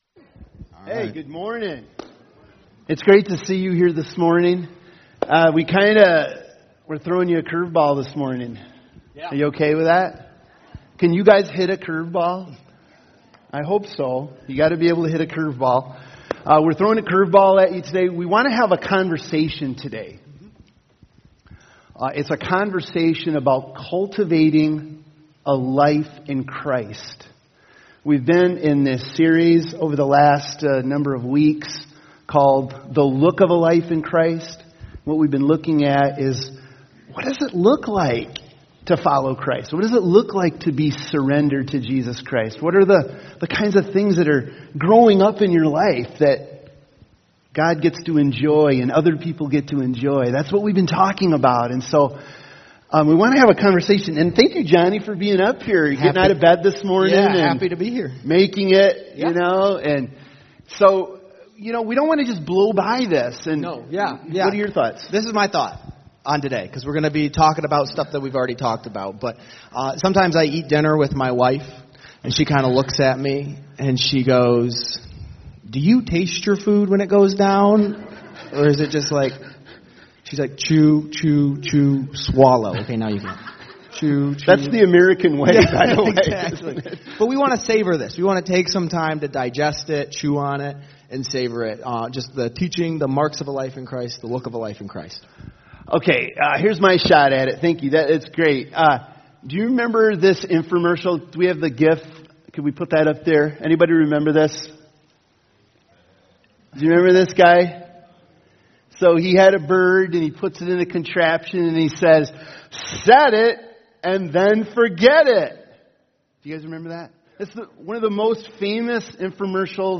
The Look of a Life in Christ Service Type: Sunday Morning « Neighbor Loving Joseph